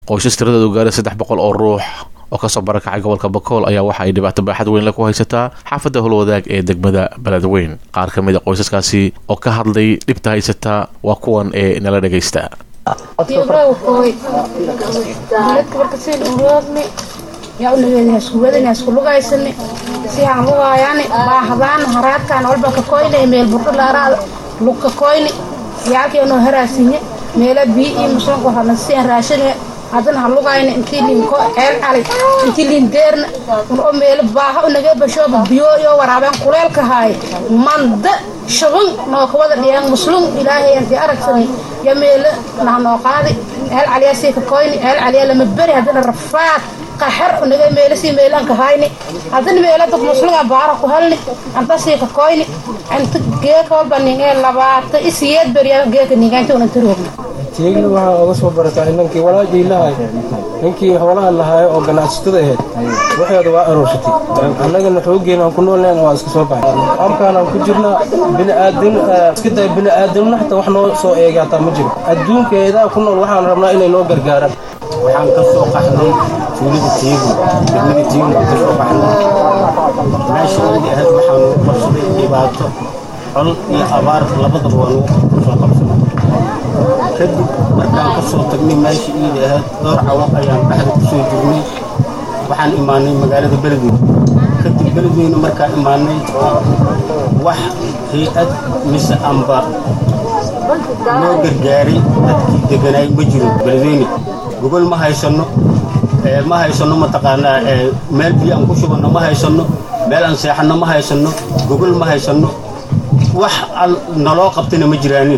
Qaar ka mid ah qoysaskaas oo ka hadlaya dhibta heysato waa kuwan ee nala dhegeysta.